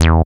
77.06 BASS.wav